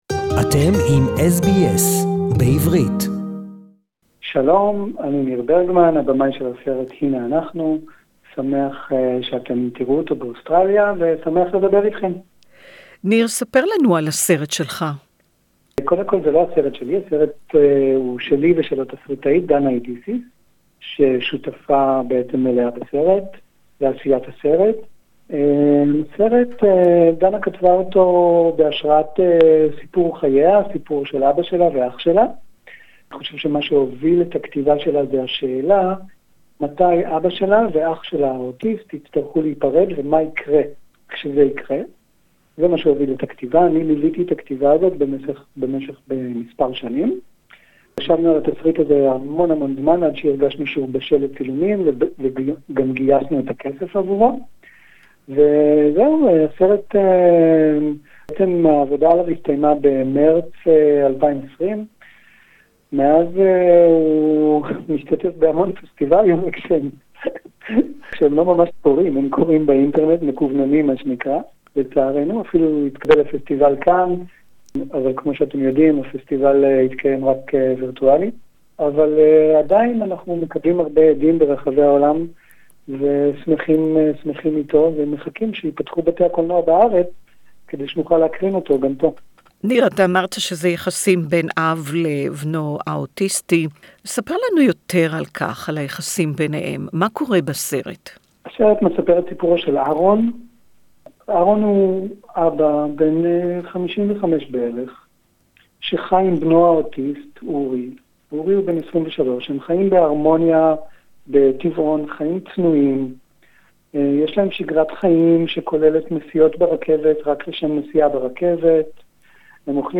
The Jewish International film Festival (JIFF) is back around Australia from 17-February Our guest is Nir Bergman: Director of the movie, Here we are, Hine Anachnu Aharon has devoted his life to raising his autistic son Uri.